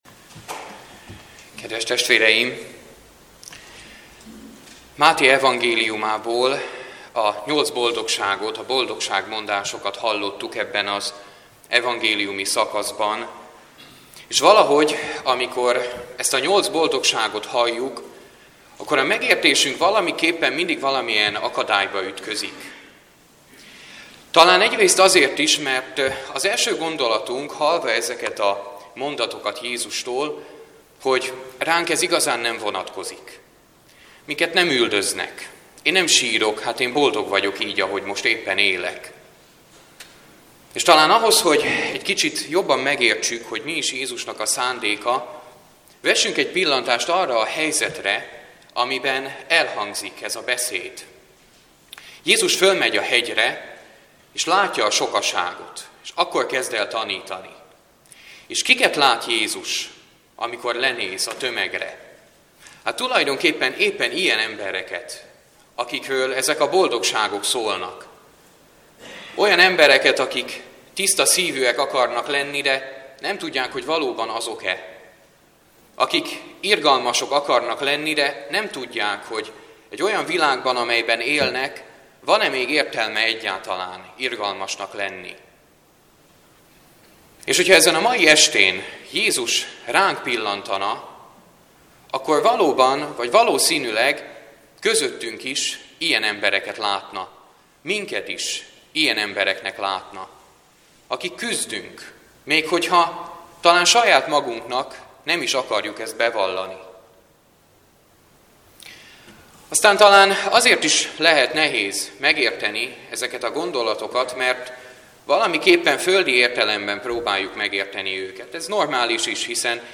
ÖKUMENIKUS IMAHÉT - Tanuljatok meg jót cselekedni!